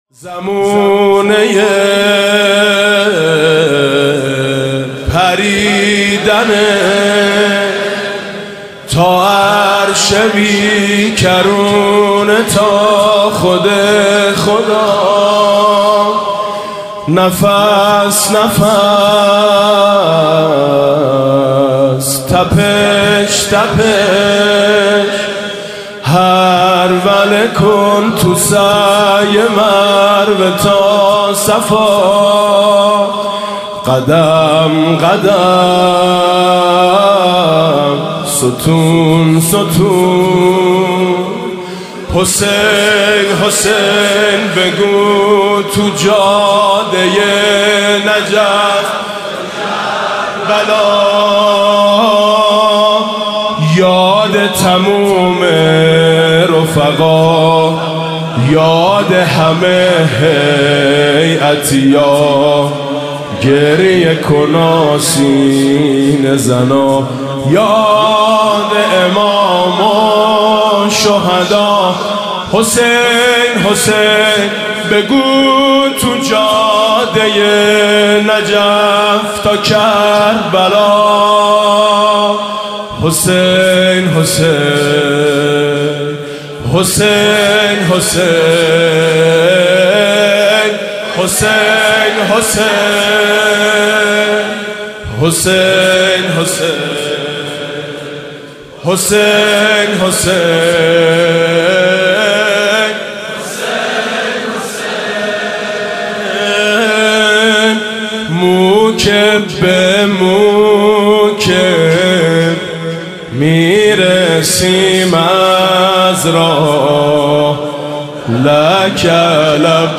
(زمینه)